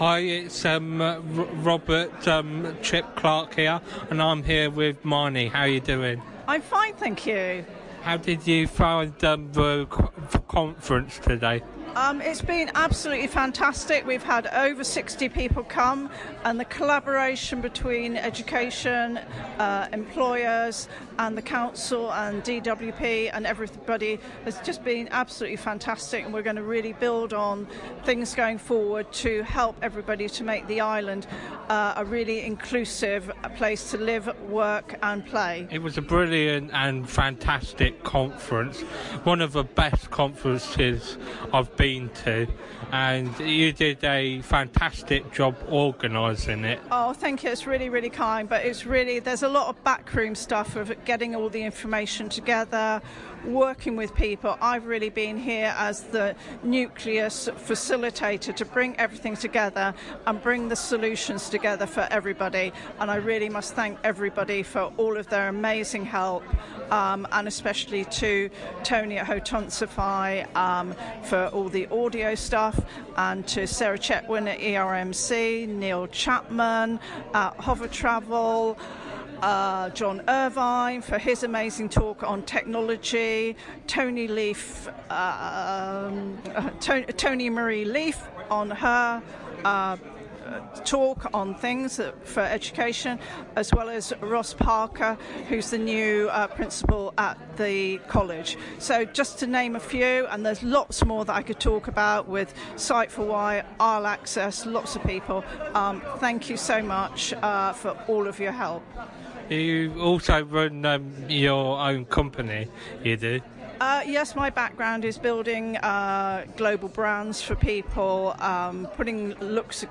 at The Inclusive Island Conference
Interview